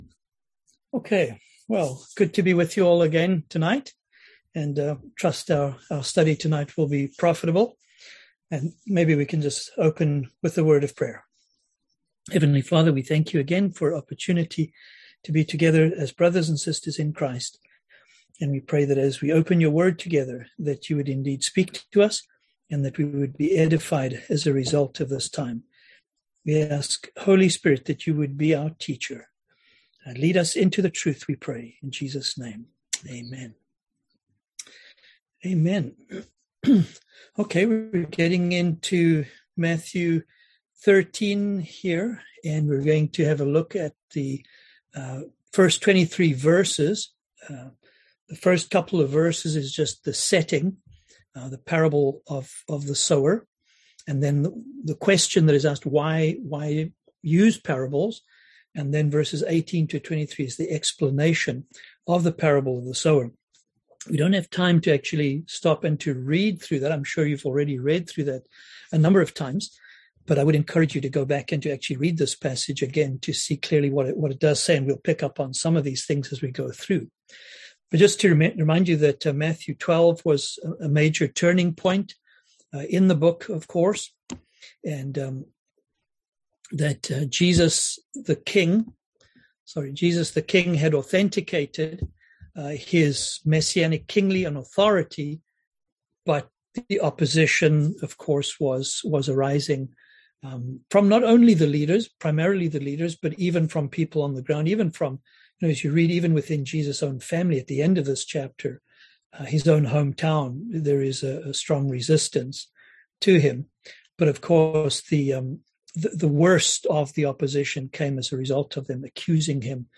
Series: Kingdom Parables 2021 Passage: Matthew 13 Service Type: Seminar